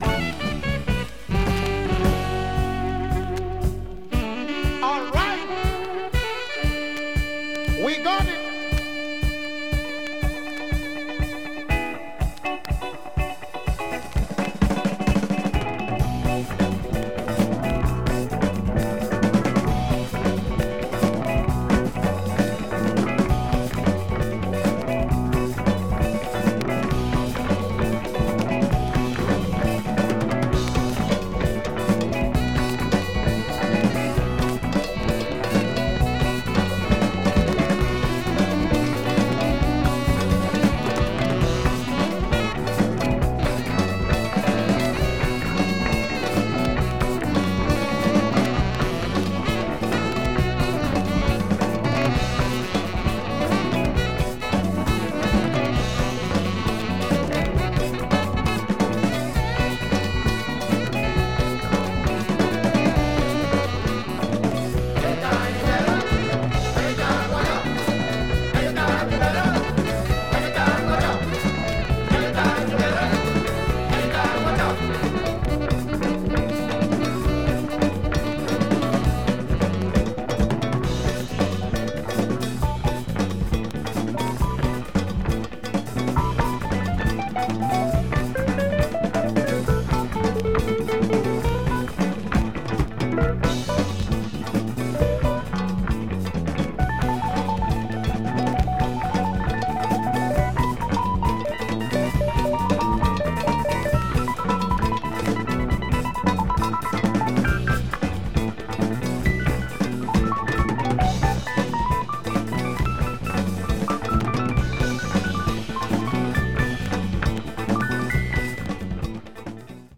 Killer Caribbean groove